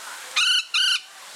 Bird Sounds
1. Golden Conure